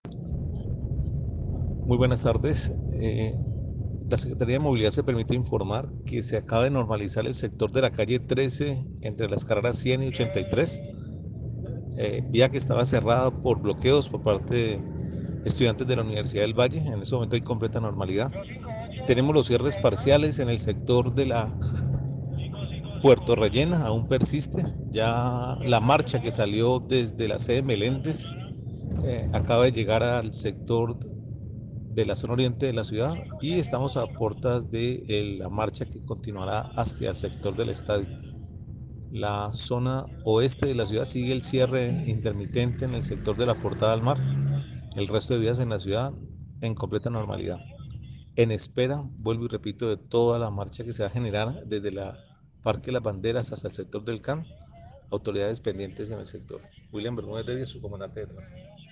También reportan, siendo las 4:30 de la tarde, normalidad en las vías hacia el sur de la ciudad, después de que estudiantes de Univalle mantuvieran cerrada la vía. Este es el reporte de normalidad a esta hora: